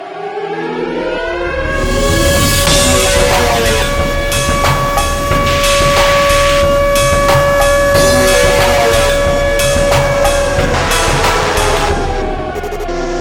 typa shi you might hear after the 2nd trumpet blow
I also added some cool sirens 'cause they're cool!